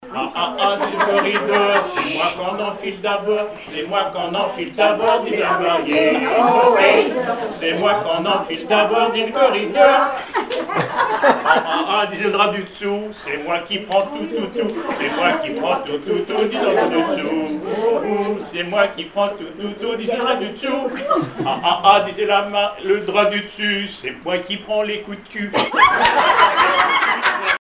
Informateur(s) Club d'anciens de Saint-Pierre association
Genre énumérative
Catégorie Pièce musicale inédite